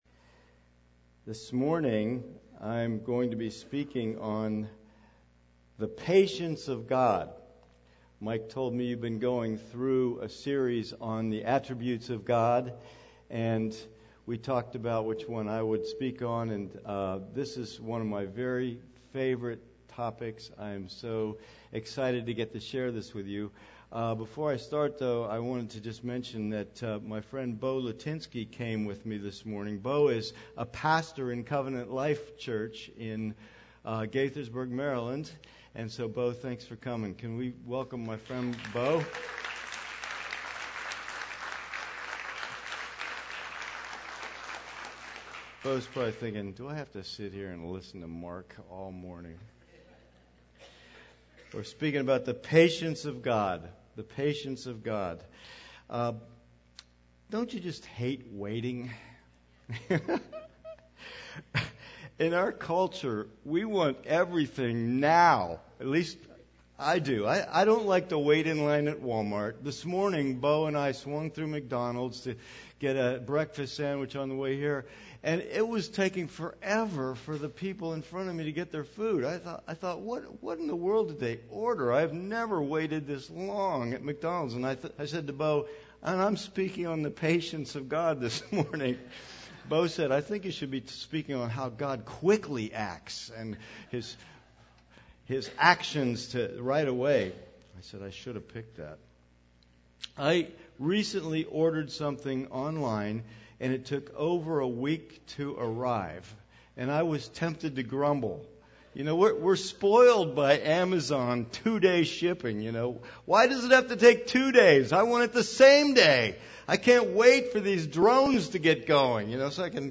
Download the weekly Bible Study that goes with this sermon.